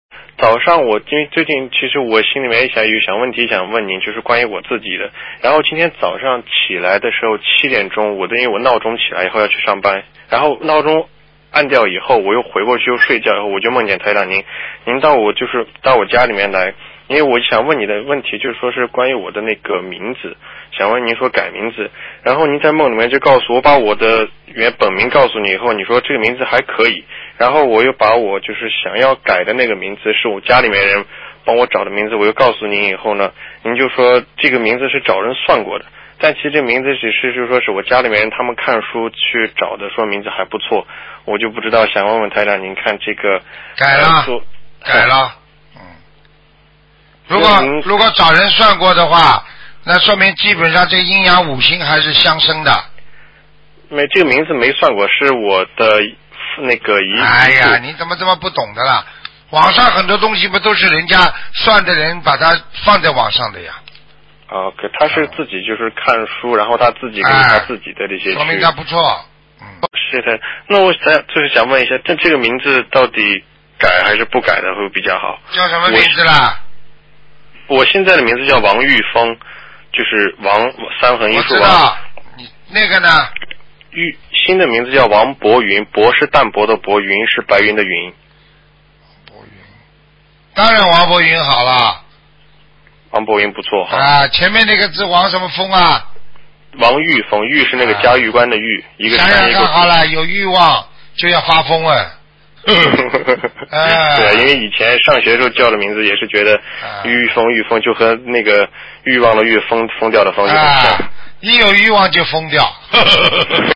目录：2016年剪辑电台节目录音_集锦